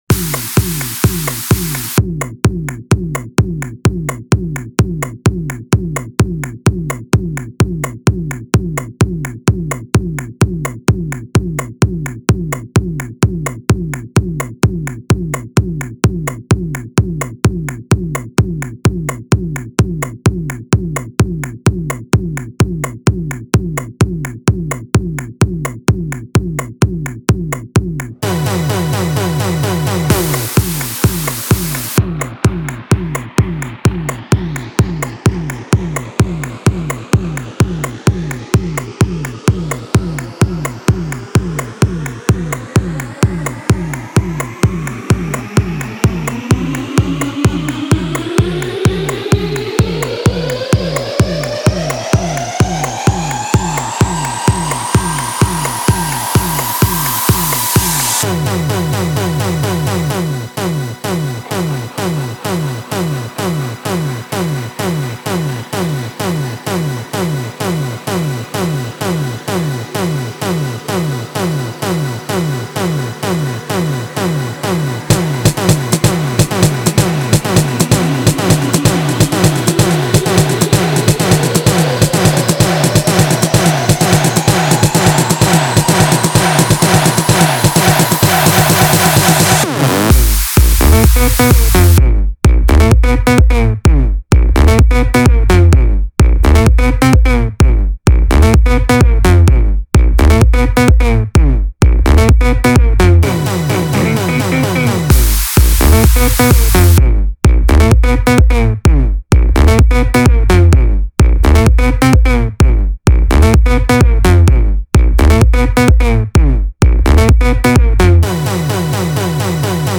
Жанр: Electro House